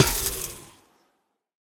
Minecraft Version Minecraft Version 1.21.5 Latest Release | Latest Snapshot 1.21.5 / assets / minecraft / sounds / block / trial_spawner / break1.ogg Compare With Compare With Latest Release | Latest Snapshot
break1.ogg